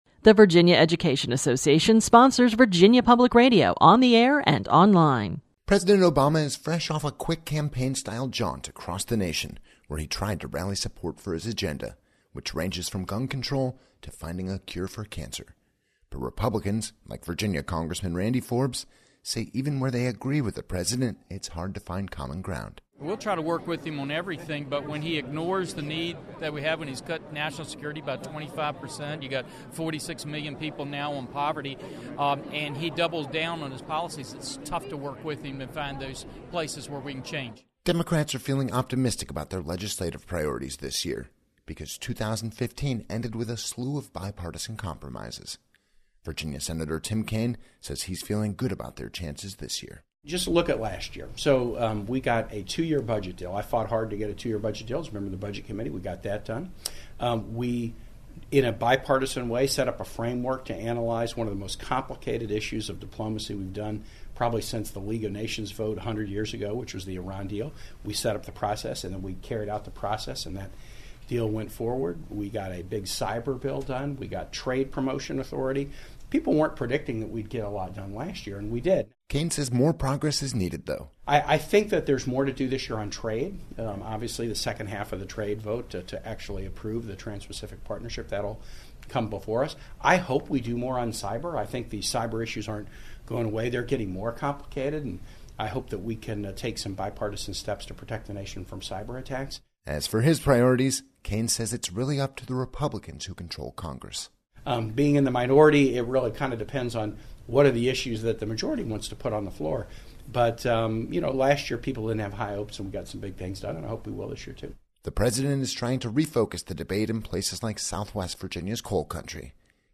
reports from the capitol.